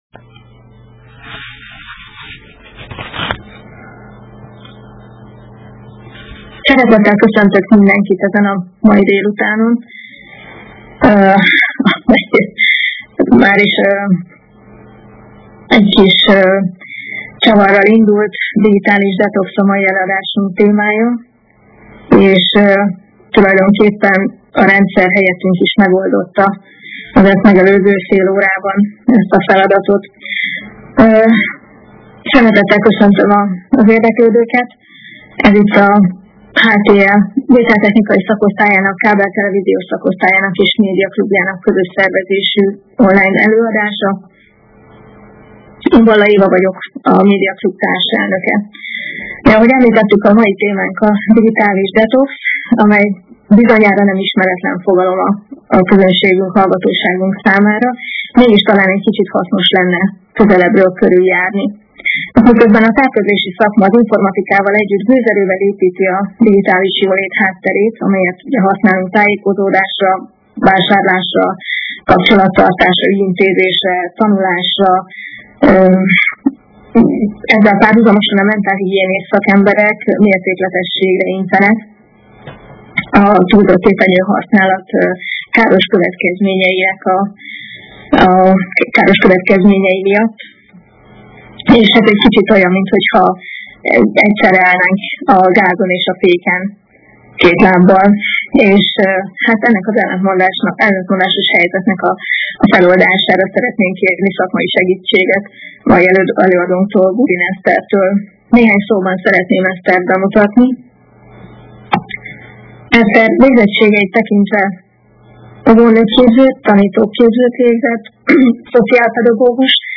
A Vételtechnikai és a Kábeltelevíziós Szakosztály, valamint a Média Klub meghívja az érdeklődőket az alábbi előadásra: